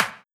SNARE124.wav